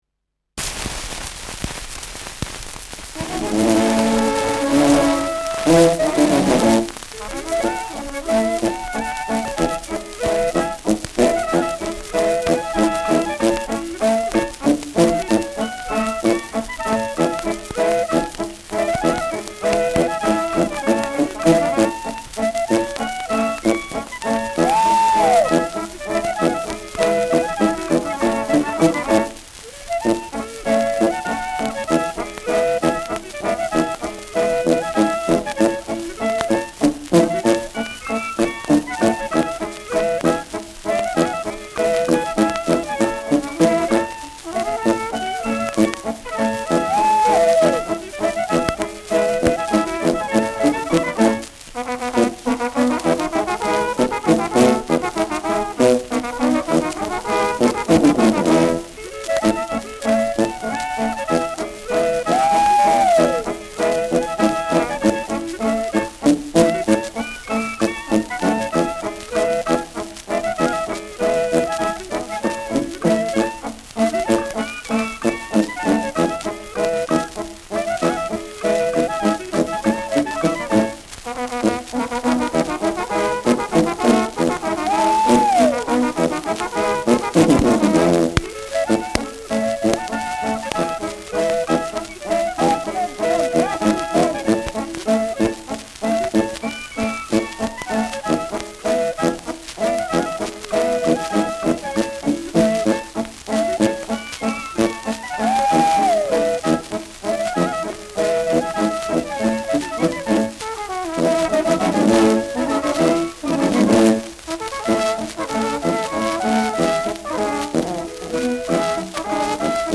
Schellackplatte
Mit Klopfgeräuschen, Juchzern, Ausrufen.